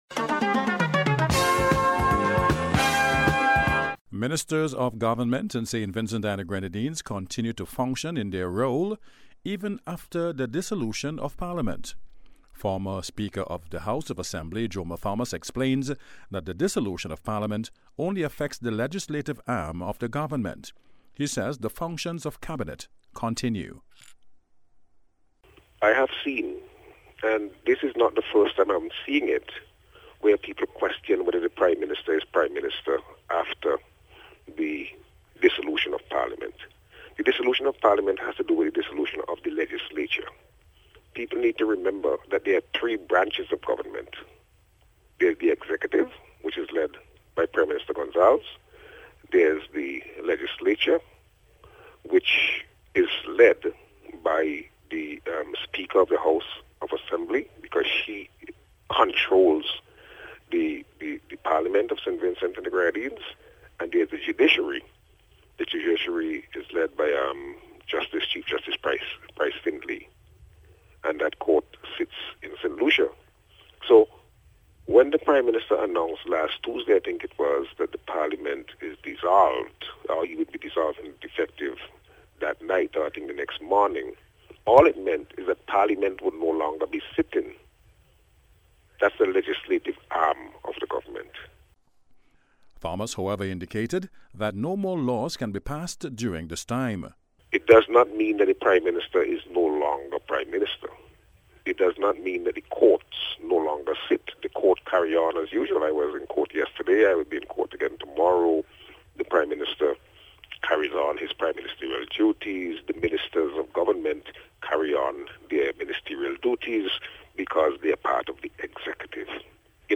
NBC’s Special Report- Tuesday 5th November,2025